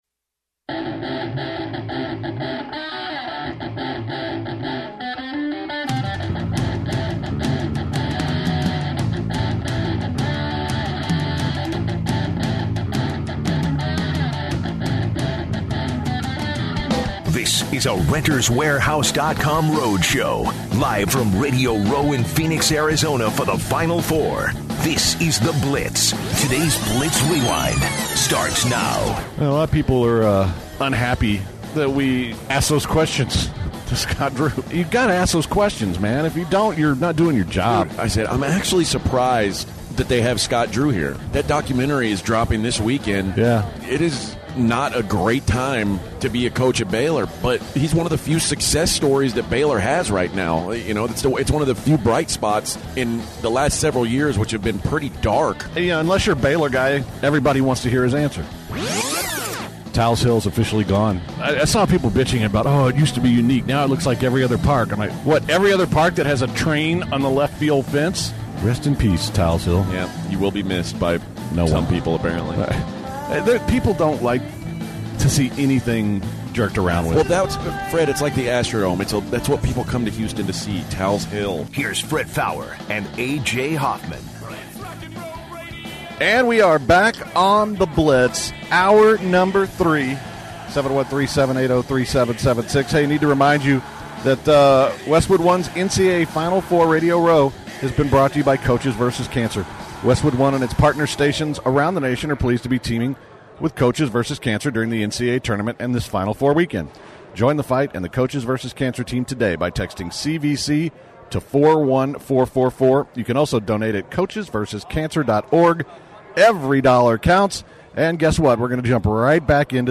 In the final hour of The Blitz from radio row at the Final Four